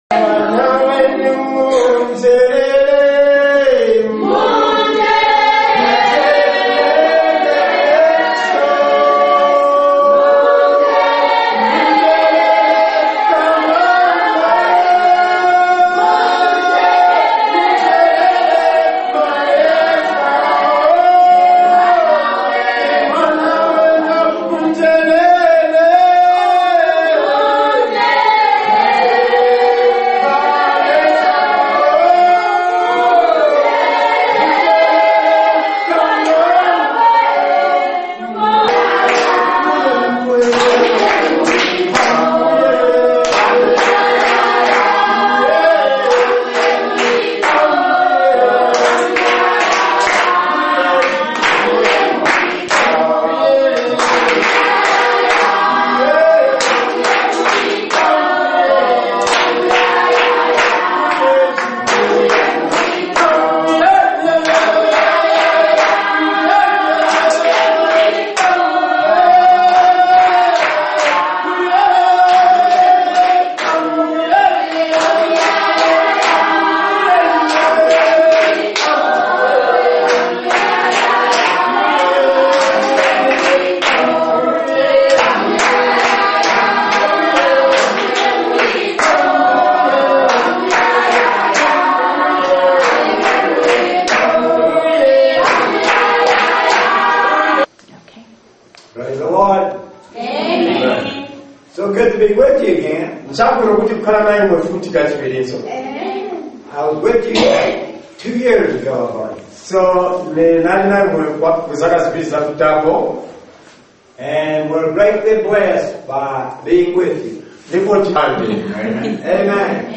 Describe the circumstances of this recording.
Paul Planted, Apollos Watered, And God Gave The Increase - Chilanga, Zambia (2138) Then there was the voice from heaven that spake again.